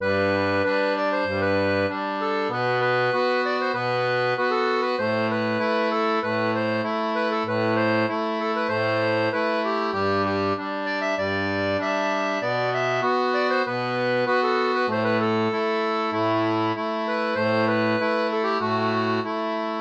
Morceau transposé en sol majeur.
Pop-Rock